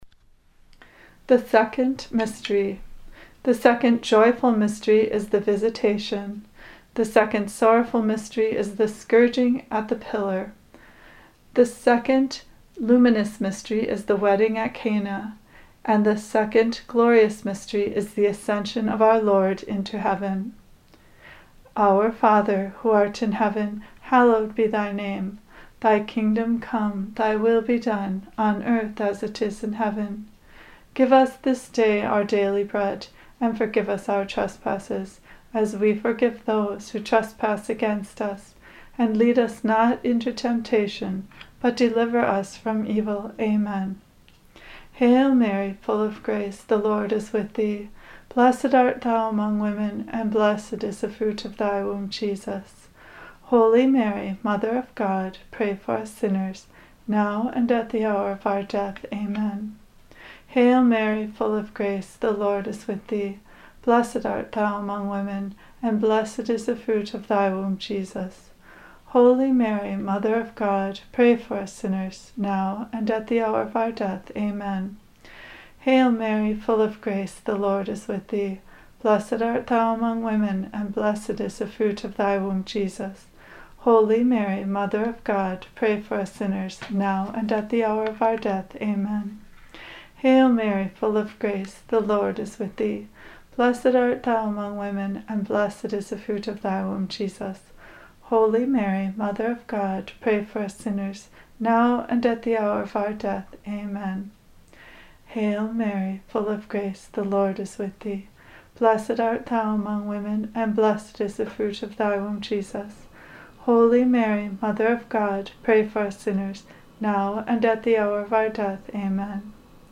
Below you will find some information and the recitation of the Rosary.